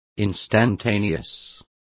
Also find out how instantaneo is pronounced correctly.